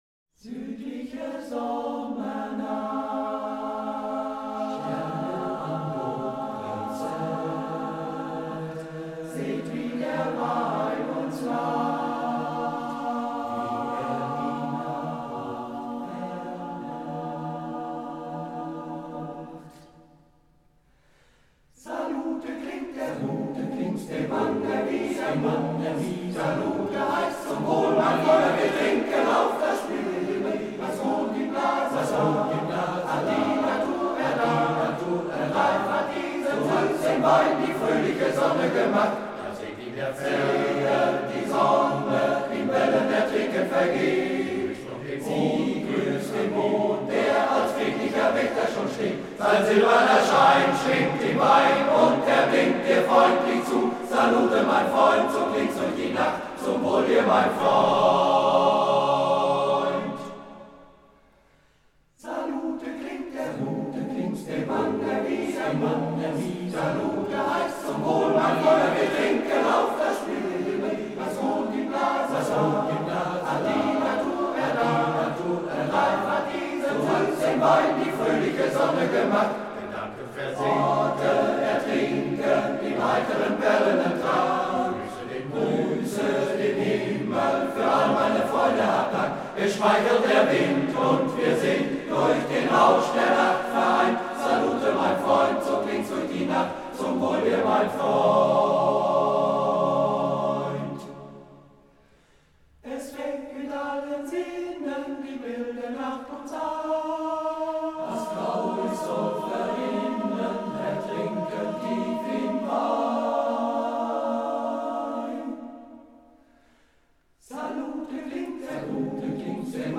Hier klicken Zur Karte der Stelenstandorte "Südliche Sommernacht" gesungen vom MGV "Cäcila" Volkringhausen e.V. Um das Lied zu hören, bitte demnächst auf unser Bild klicken.